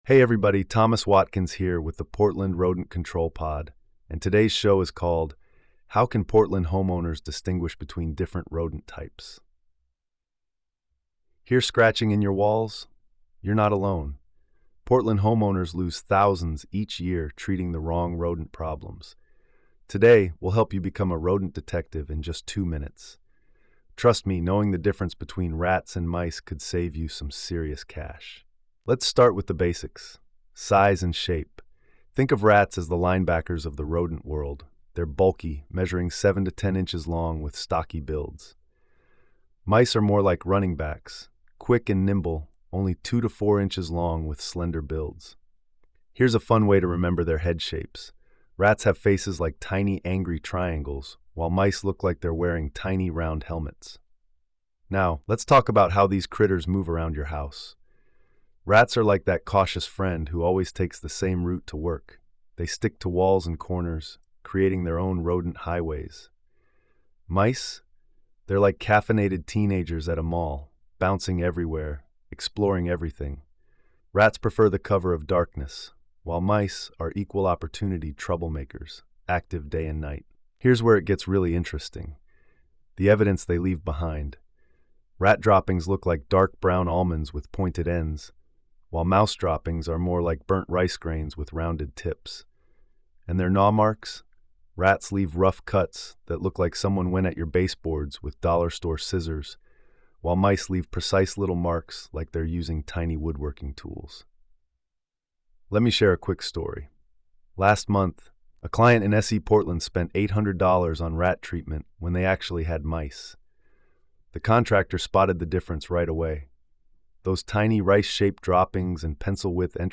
pest control veteran